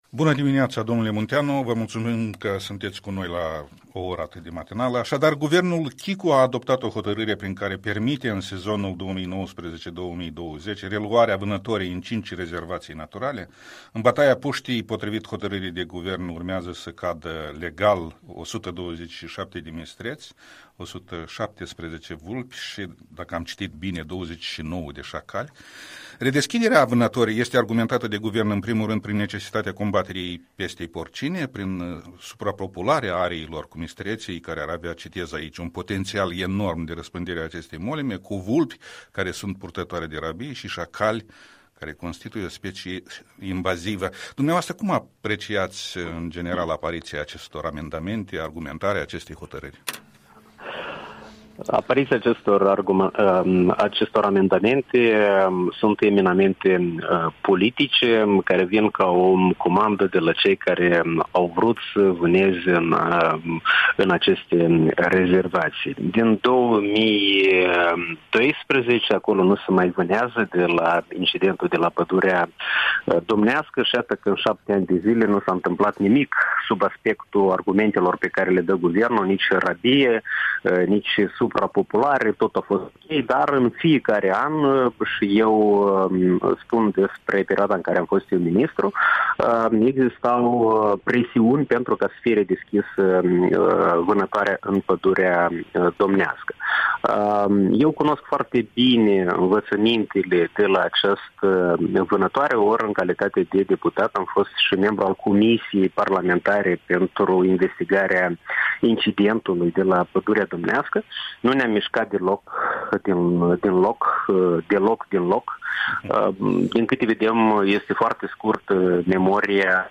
Interviu cu Valeriu Munteanu, fost ministru al mediului